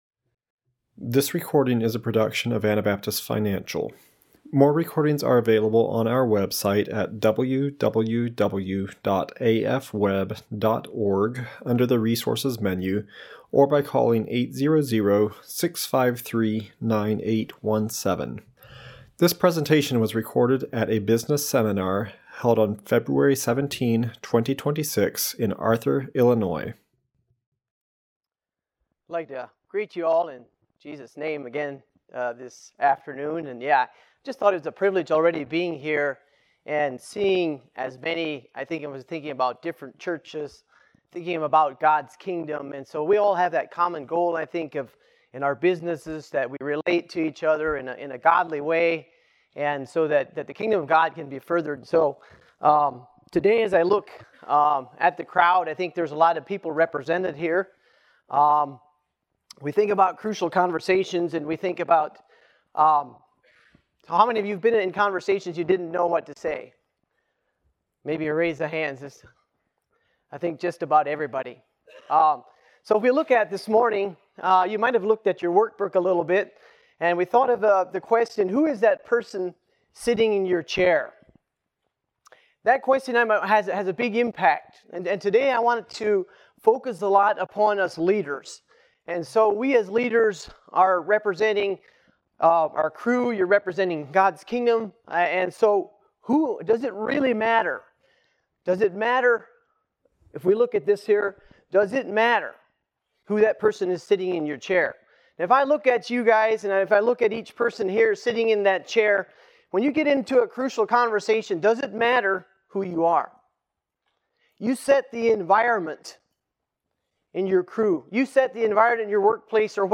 Illinois Business Seminar 2026